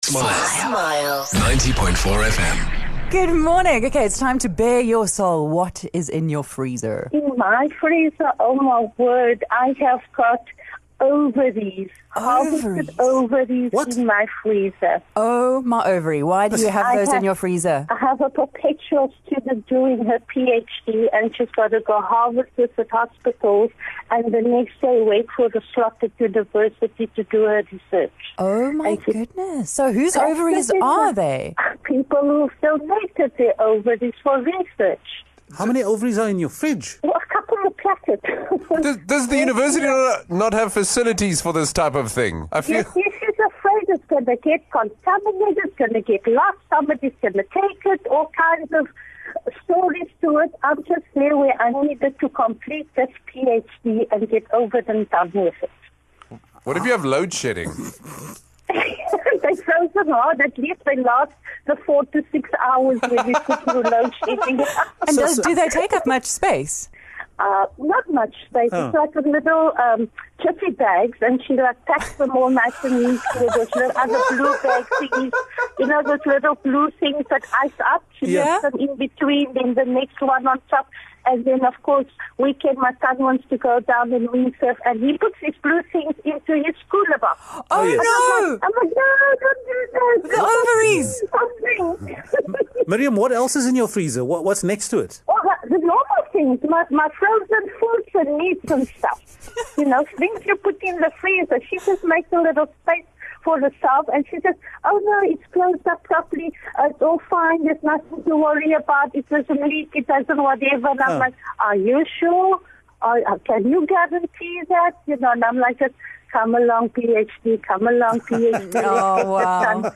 We had a conversation about the strangest item in your freezer. We got a call that scared us a bit and we almost called the cops on her, but she had an explanation for it.